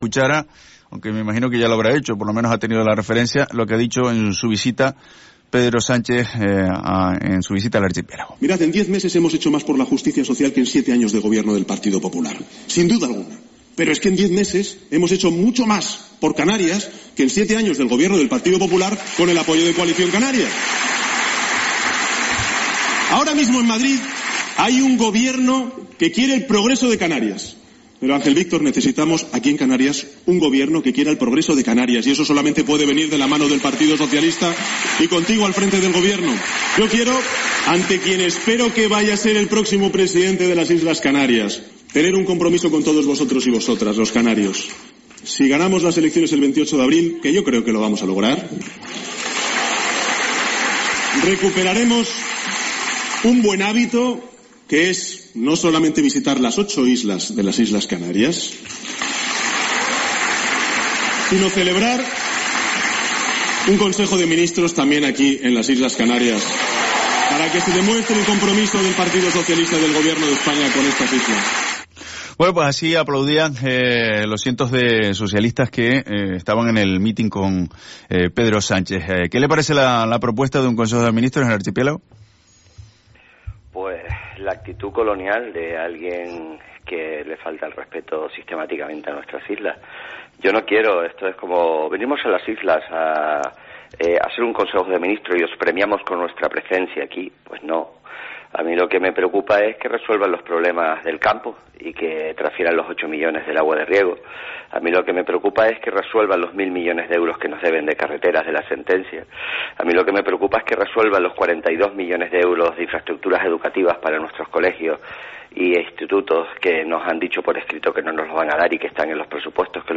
Entrevista a Fernando Clavijo